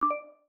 Message Ping X2 3.wav